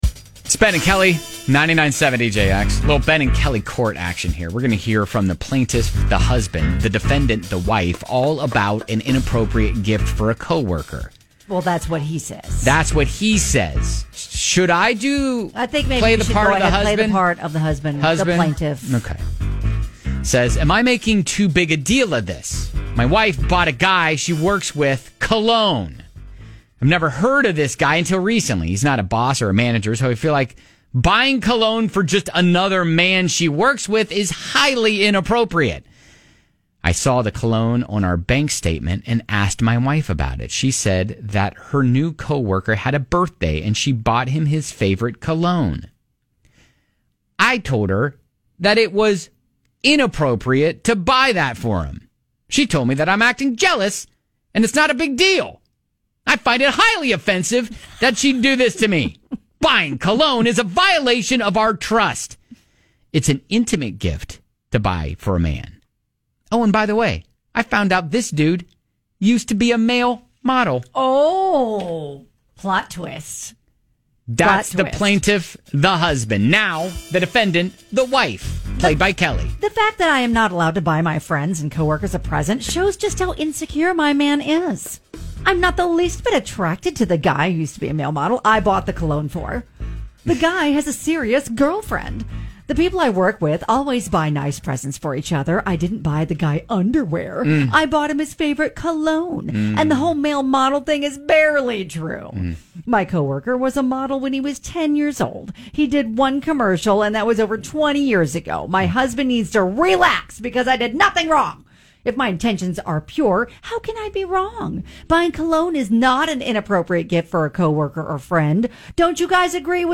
Is cologne an inappropriate gift for a coworker? We hear from both sides - a husband who thinks it’s a wildly inappropriate gift, and the wife who defends her right, whose intentions are pure, and claims she did nothing wrong.